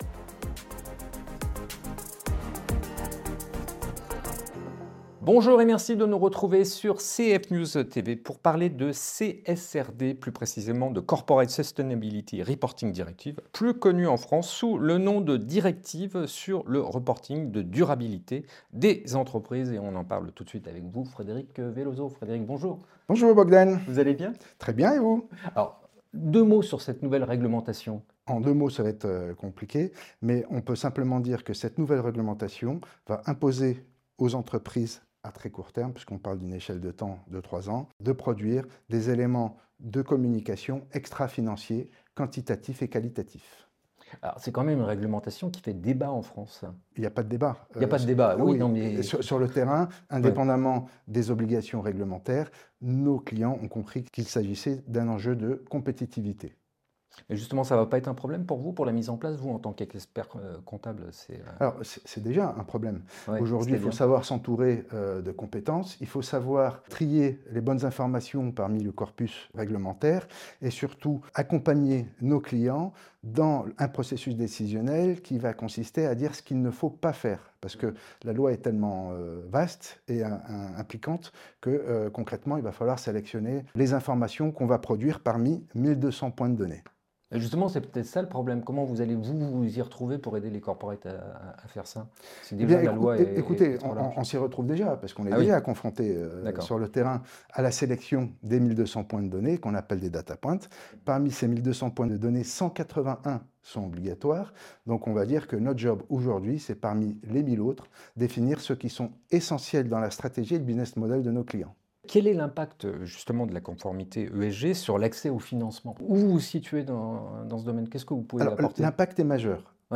Interview – La CSRD : un enjeu stratégique pour les entreprises